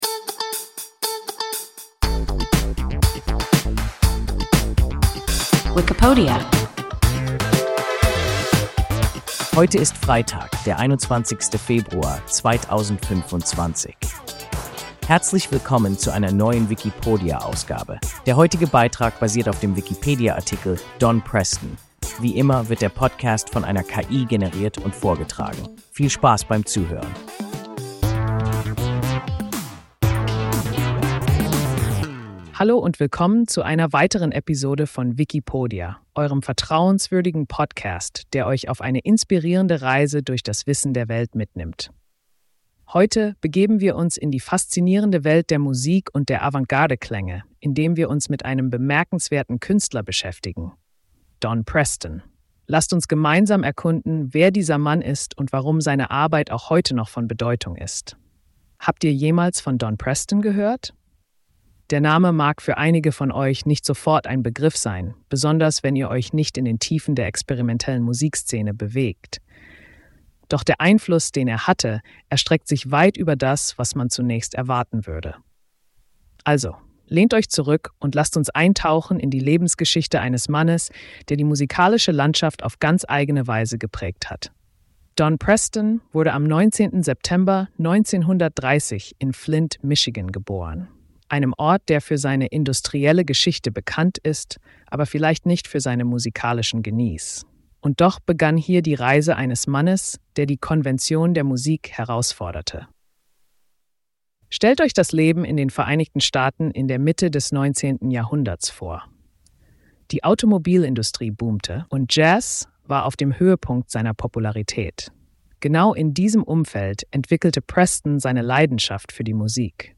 Don Preston – WIKIPODIA – ein KI Podcast